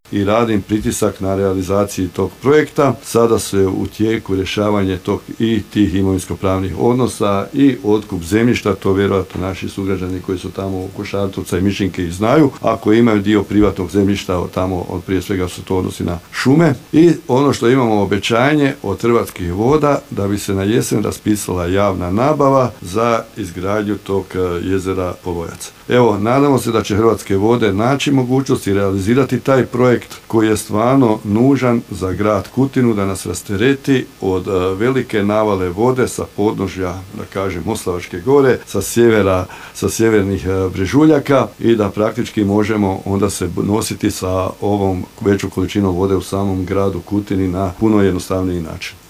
Gradonačelnik Kutine Zlatko Babić osvrnuo se na nedavnu elementarnu nepogodu te na akumulaciju Polojac koja bi pomogla pri rješavanju problema plavljenja grada